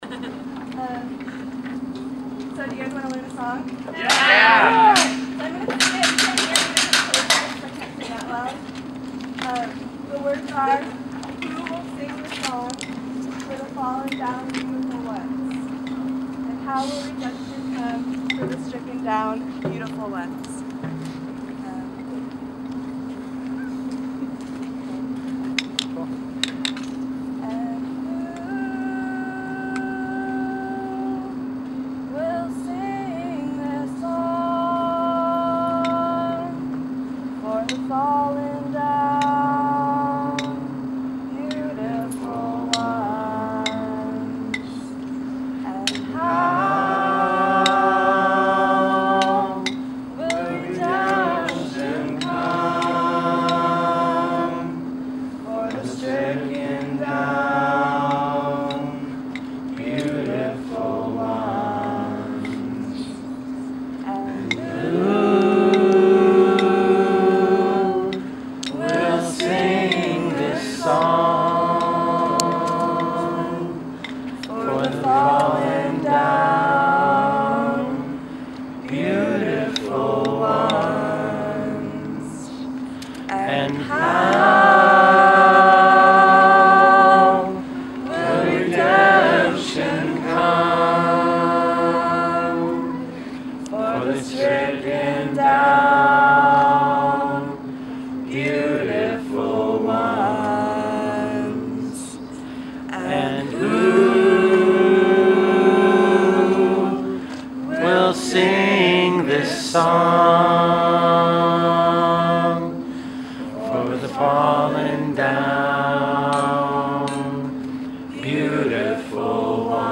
Noise Demonstration at the County Jail in Solidarity with the Occupy Santa Cruz Arrestees
Approximately 50-75 community members brought percussion instruments and five gallon plastic water jugs and proceeded to drum in mass to communicate to the prisoners inside of the jail that community members would not give up support of their cause, and also to show the "compassion and fire" felt for those interned, and to stand strong when faced with police repression locally.
It was then asked if anyone wanted to sing a song, and a woman lead the group in the repeated choruses, "Who will sing the song, for the fallen down, beautiful ones, and how will redemption come for the stricken down beautiful ones?"